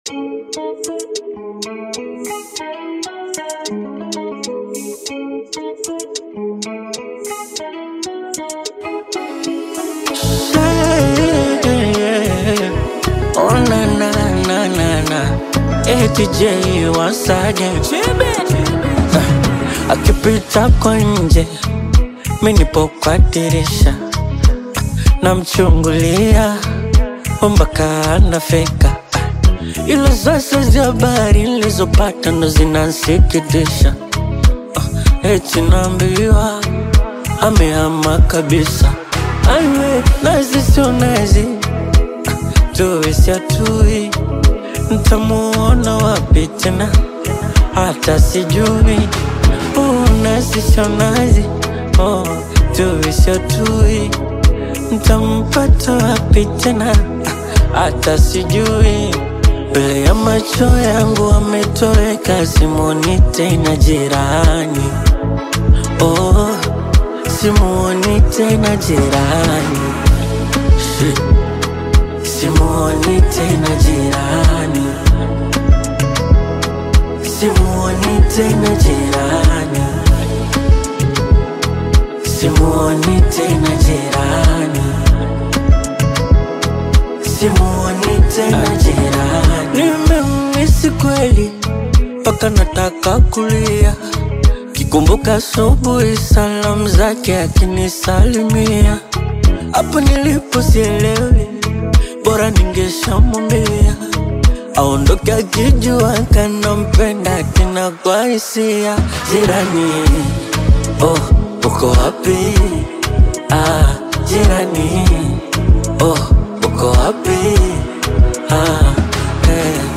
soulful Bongo Flava single
Genre: Bongo Flava